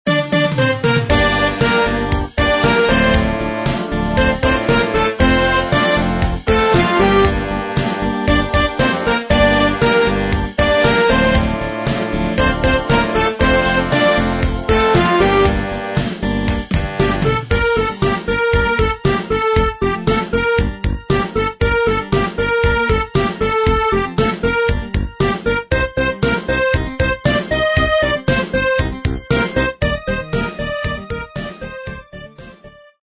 Вот кусок мелодии песни, он начинается с припева, кусок маленький но больше я не нашёл.